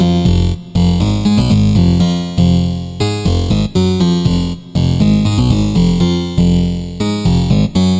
Tag: 120 bpm Electro Loops Synth Loops 1.35 MB wav Key : Unknown